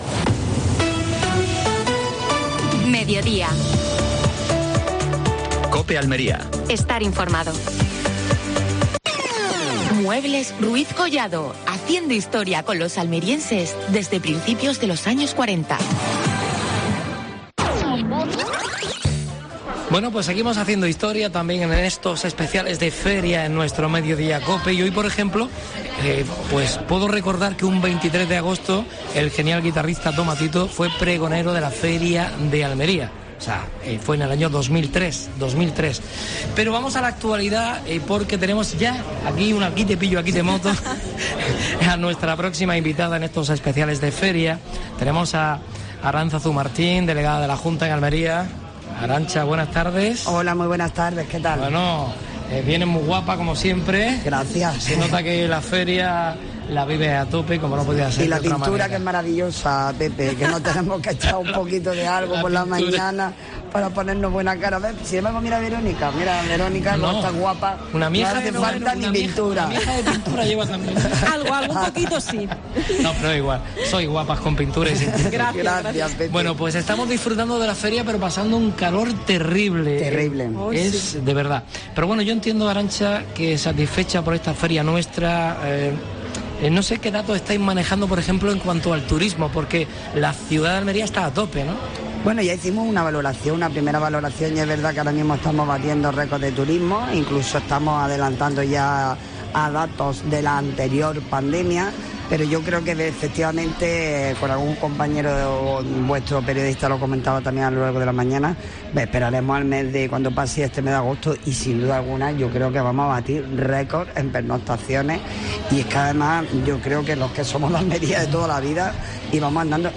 AUDIO: Programa especial de la Feria de Almería. Entrevista a Aránzazu Martín (delegada de la Junta de Andalucía en Almería).